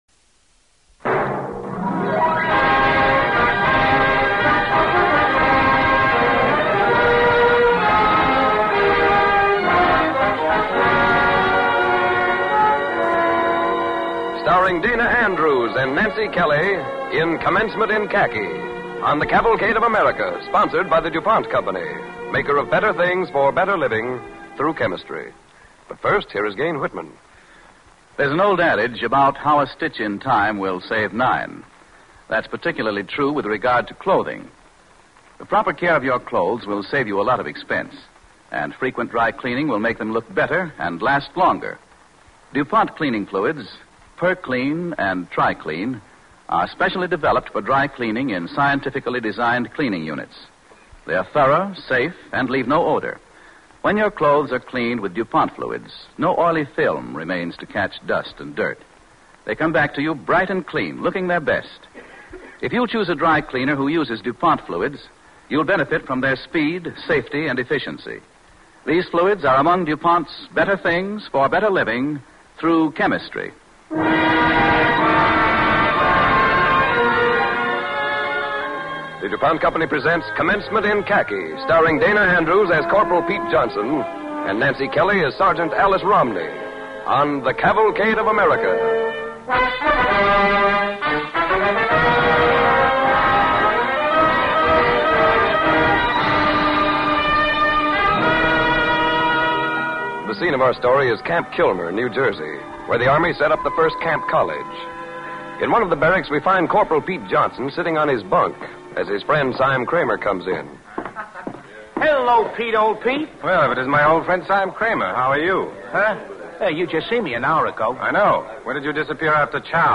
starring Dana Andrews and Nancy Kelly